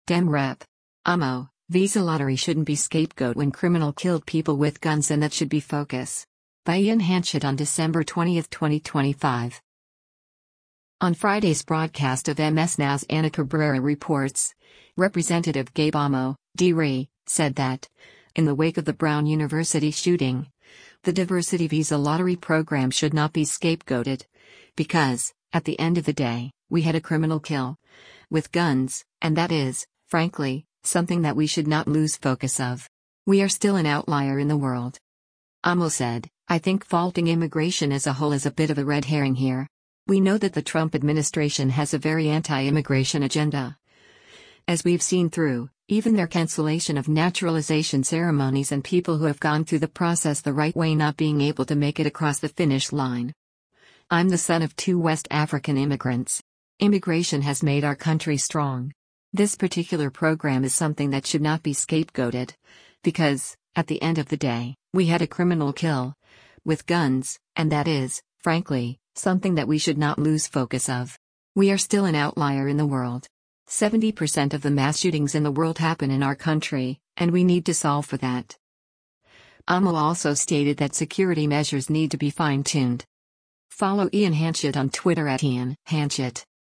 On Friday’s broadcast of MS NOW’s “Ana Cabrera Reports,” Rep. Gabe Amo (D-RI) said that, in the wake of the Brown University shooting, the diversity visa lottery program “should not be scapegoated, because, at the end of the day, we had a criminal kill, with guns, and that is, frankly, something that we should not lose focus of. We are still an outlier in the world.”